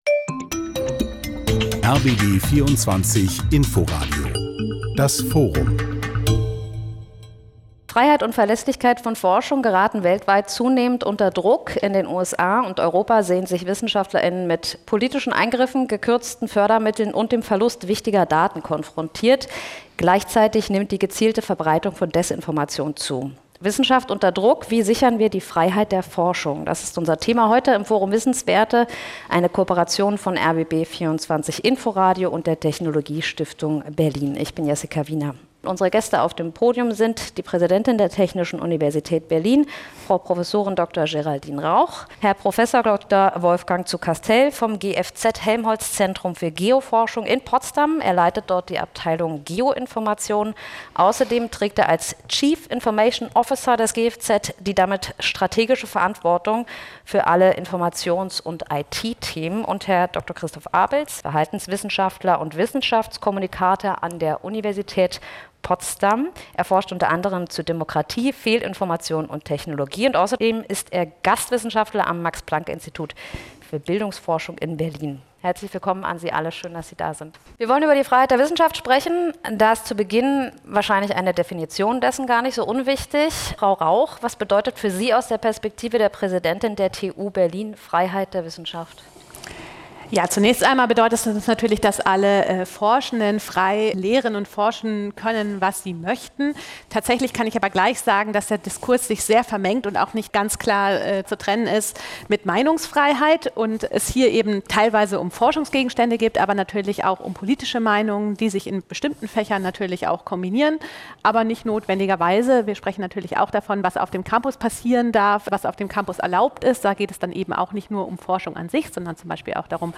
spricht mit ihren Gästen in der Technologiestiftung Berlin.